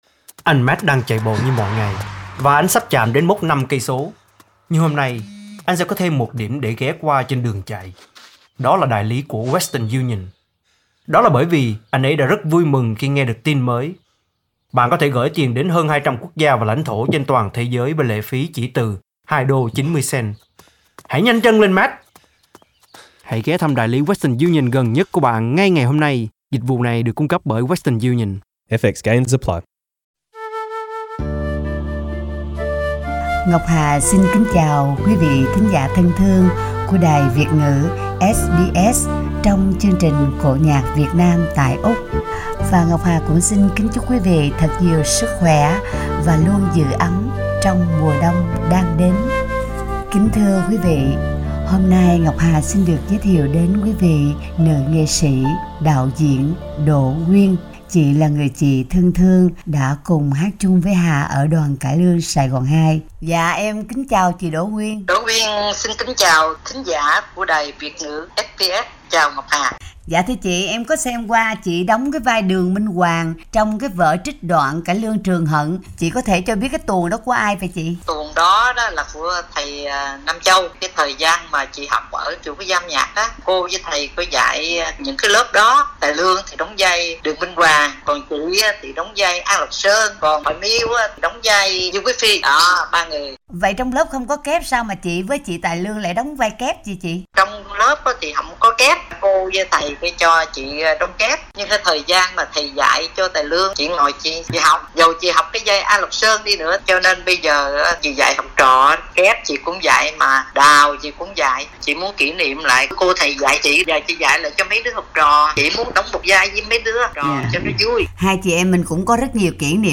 phân khoa cải lương.
giọng ca khỏe khoắn